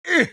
attack2.ogg